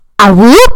AWOO [EARRAPE]
awoo-earrape.mp3